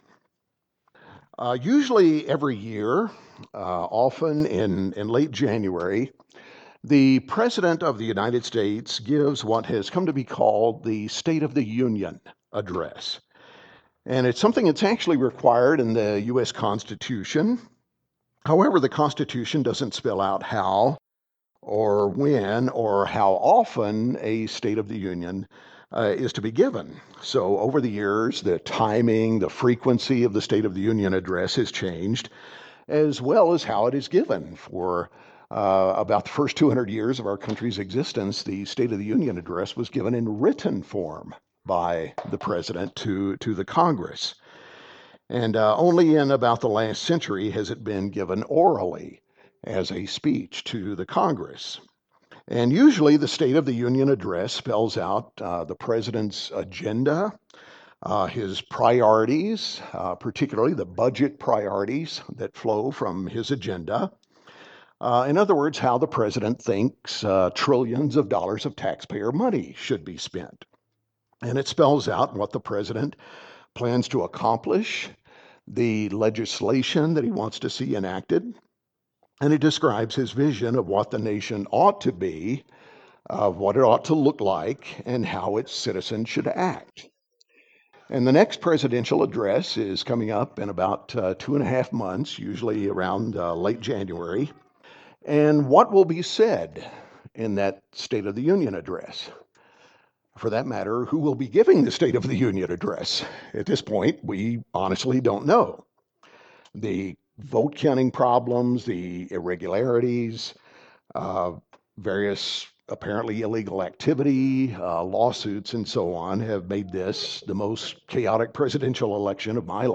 In this continuing series of sermons on Bible prophecy, we consider what God would say to the nation today if He were giving a State of the Union address. And indeed, He has already given such an address in Bible prophecy—so what does it say?